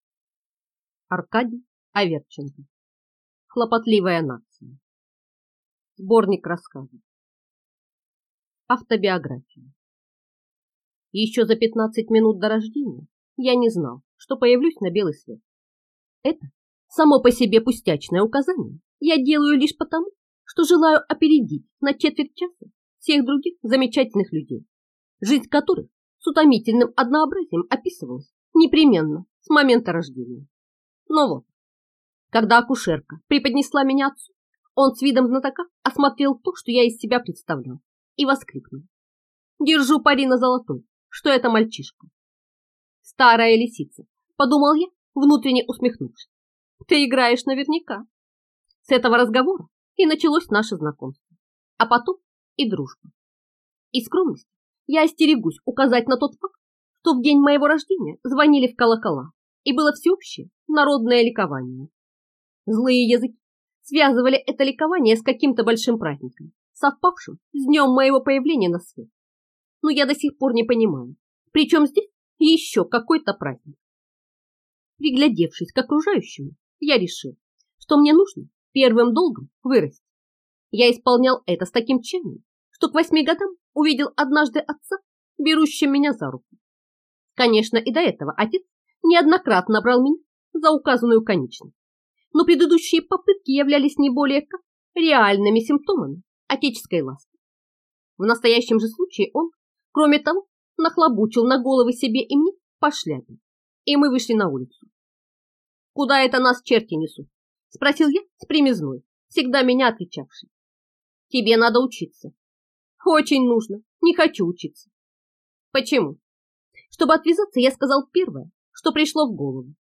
Аудиокнига Хлопотливая нация (сборник рассказов) | Библиотека аудиокниг